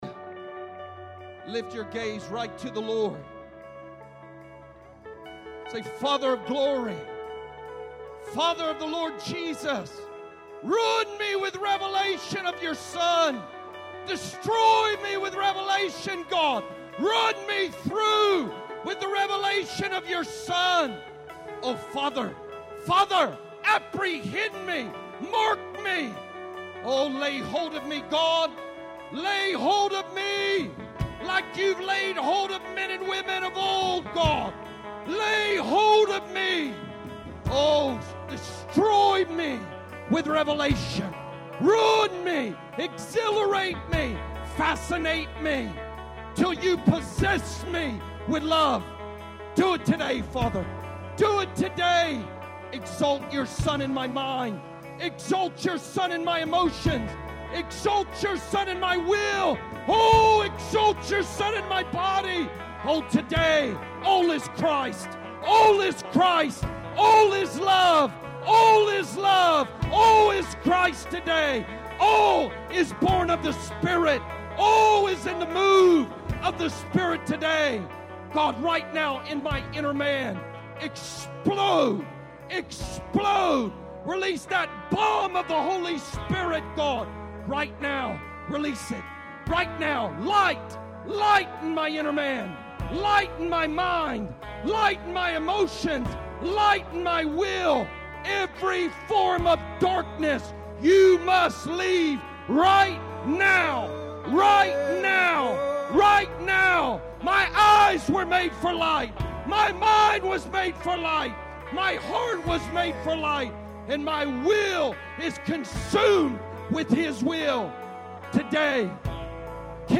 The tour ended in a conference at Mott Auditorium, named after the man who would later be named the chairman of the SVM.